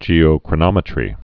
(jēō-krə-nŏmĭ-trē)